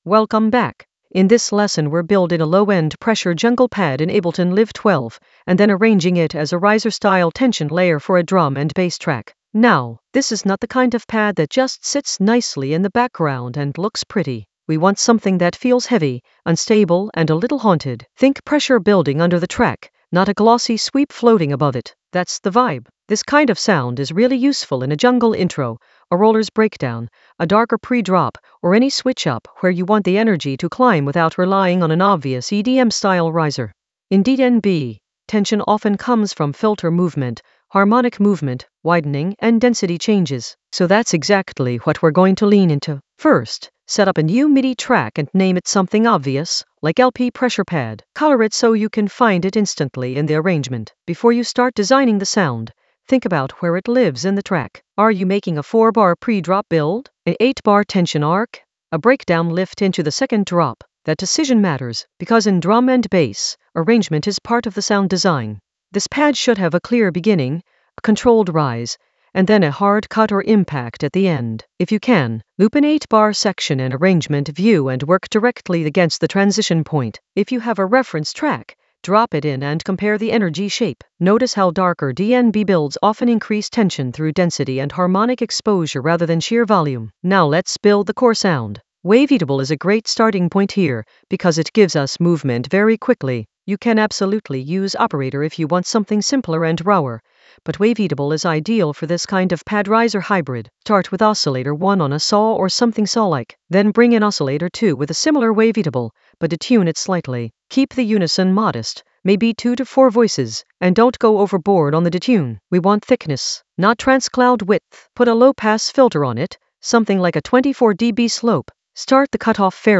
An AI-generated intermediate Ableton lesson focused on Low-End Pressure jungle pad: rebuild and arrange in Ableton Live 12 in the Risers area of drum and bass production.
Narrated lesson audio
The voice track includes the tutorial plus extra teacher commentary.